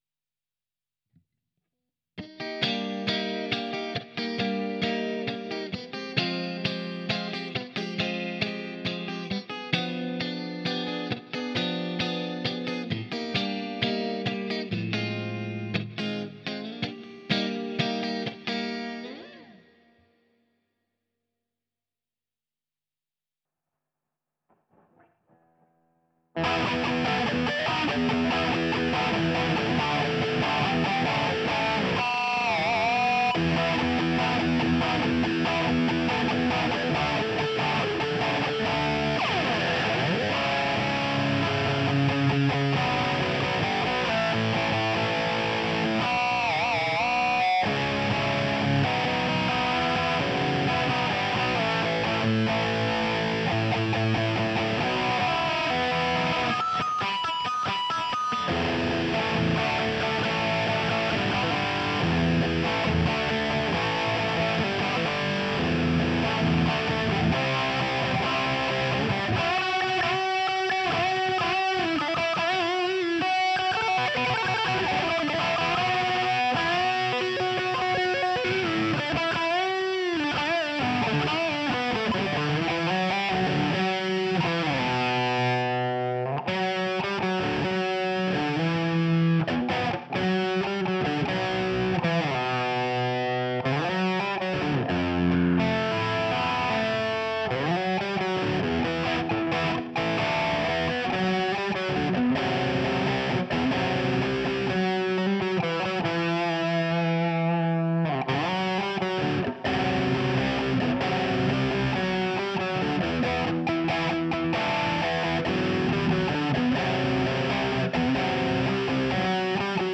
MOONのストラトをPOD-x3につなぎ、LINE-INで録音です。
非圧縮で録音でき、格好良い画面デザインです！
LINE-INなので音が良いのは当たり前・・・かも知れませんが。。